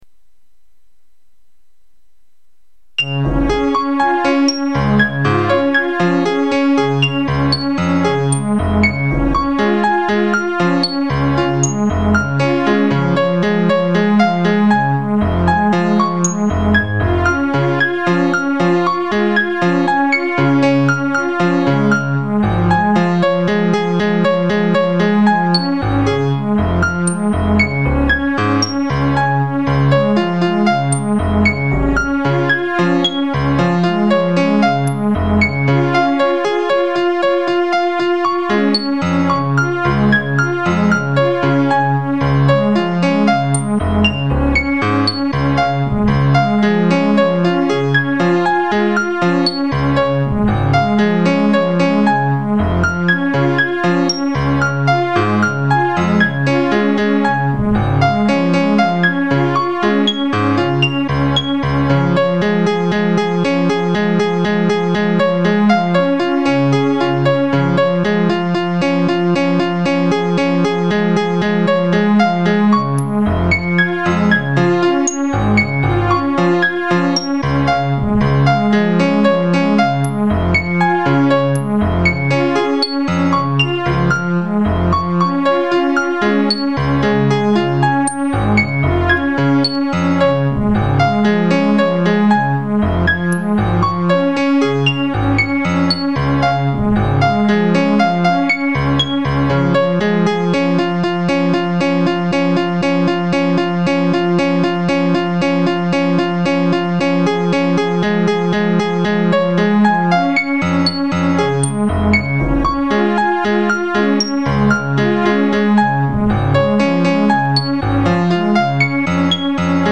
The \(t\) ranges from 0 to 3000 played at regular intervals of \(2\pi\), \(x\) is the orchestra and \(y\) is the piano.